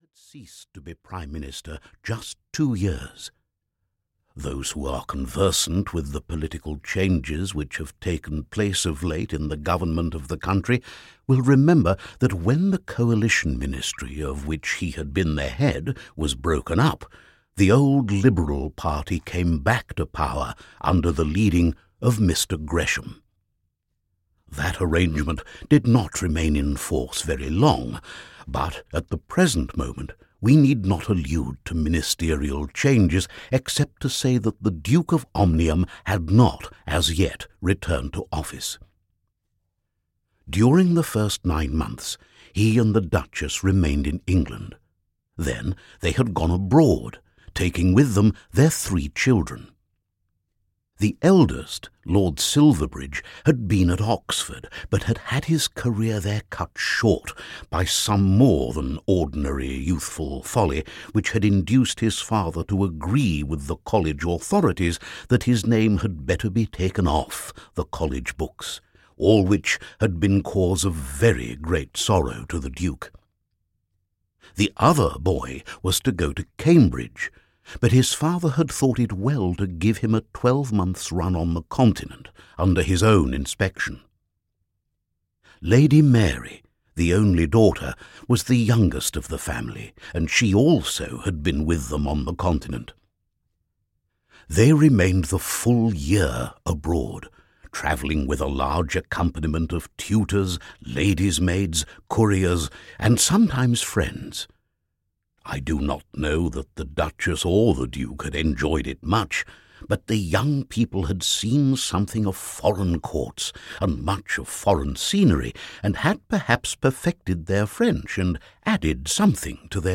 The Duke's Children (EN) audiokniha
Ukázka z knihy